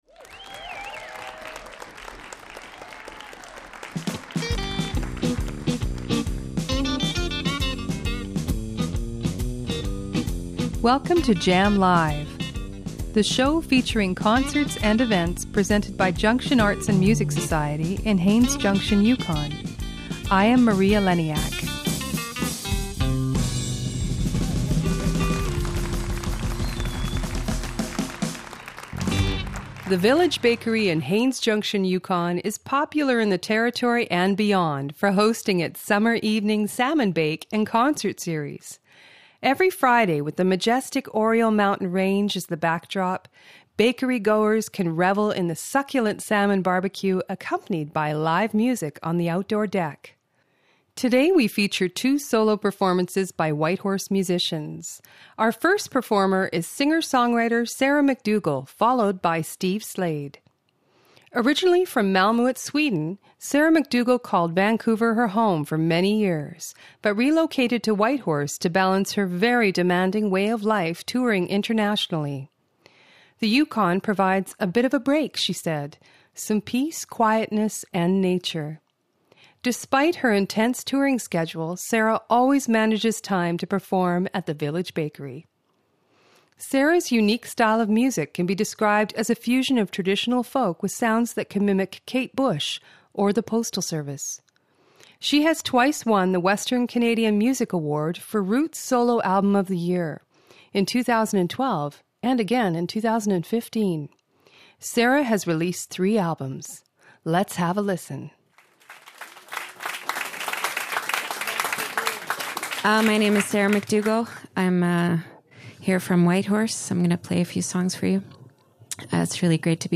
Live music recorded in Haines Junction, Yukon.